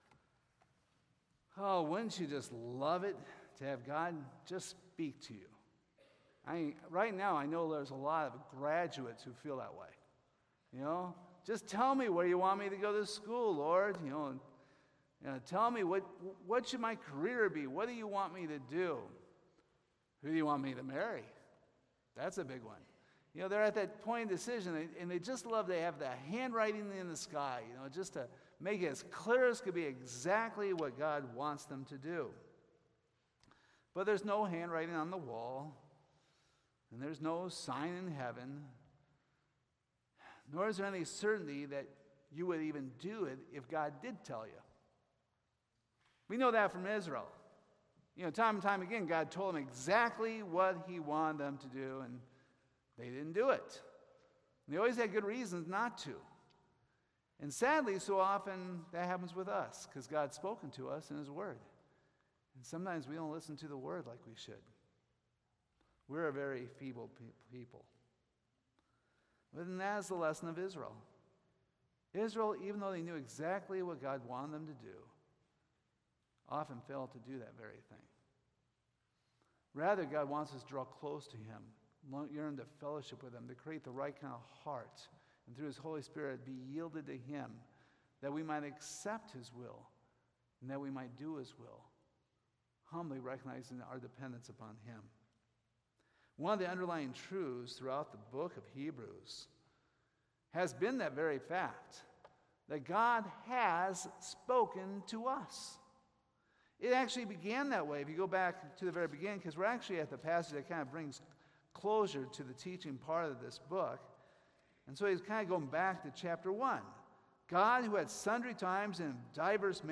Hebrews 12:25-29 Service Type: Sunday Morning Has God given us the freedom to reject him?